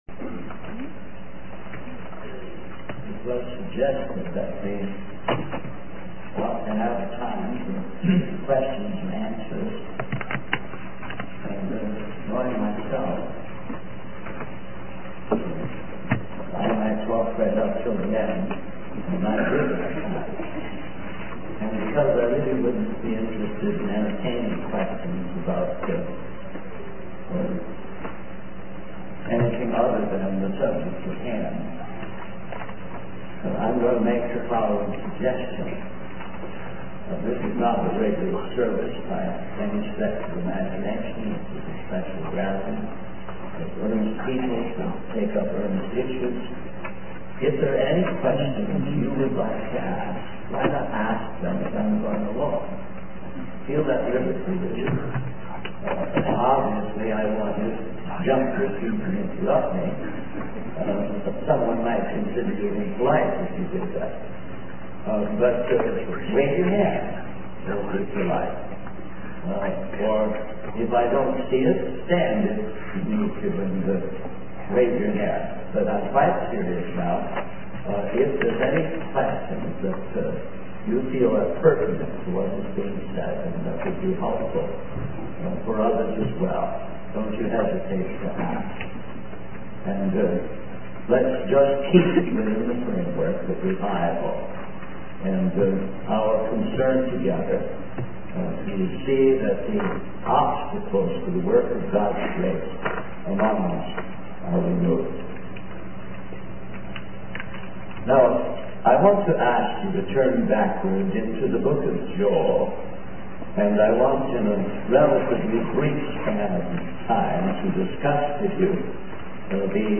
In this sermon, the preacher begins by describing a scenario of a young girl getting married to an older man, emphasizing the importance of finding the right partner in God's eyes. He then transitions to discussing the significance of passing down the stories of God's mighty acts to future generations. The preacher highlights the lack of personal experiences with God's miracles among many Christians and the need for the Bible to guide and reveal the true nature of miracles.